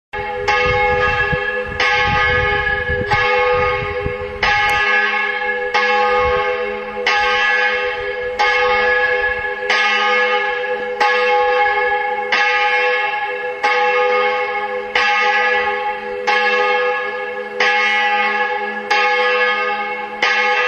Es ist die viertgrößte Marlinger Glocke. Sie ist ca. 500 kg schwer und hat den Ton Gis.